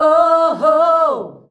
OOOHOO  E.wav